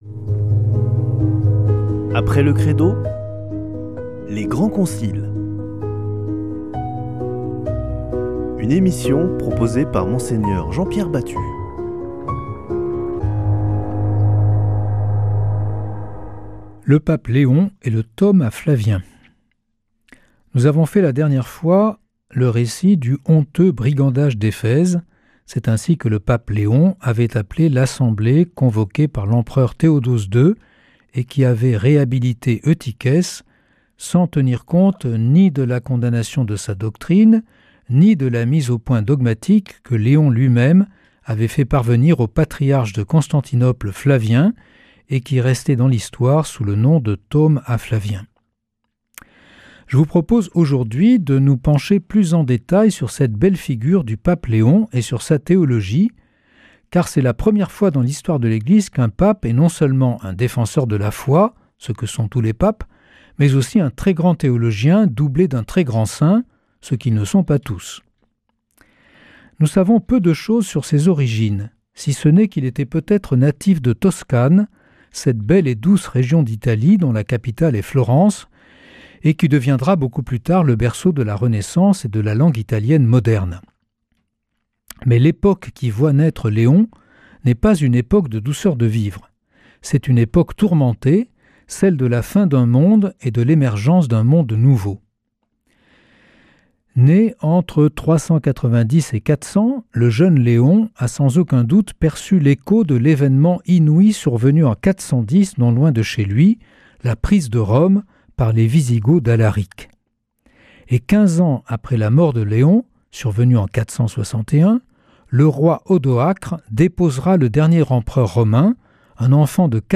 Partager Copier ce code (Ctrl+C) pour l'intégrer dans votre page : Commander sur CD Une émission présentée par Mgr Jean-Pierre Batut Evêque auxiliaire de Toulouse Voir la grille des programmes Nous contacter Réagir à cette émission Cliquez ici Qui êtes-vous ?